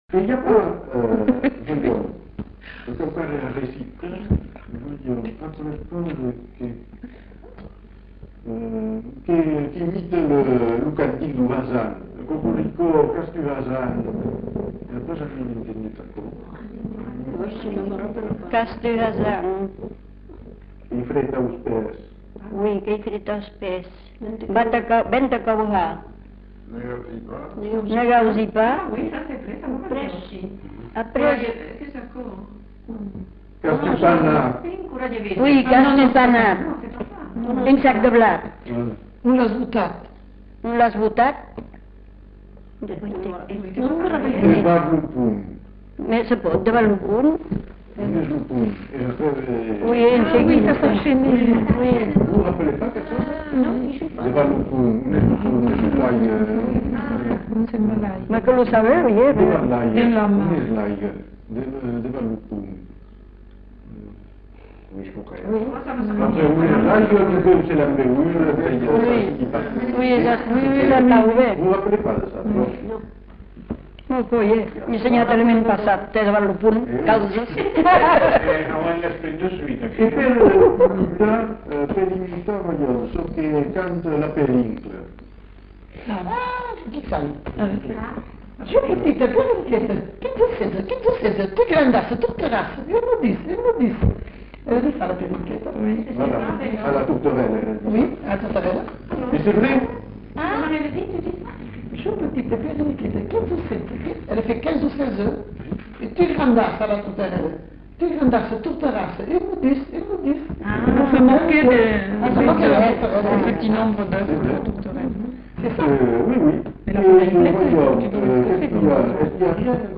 Lieu : Villandraut
Effectif : 1
Production du son : récité
Classification : mimologisme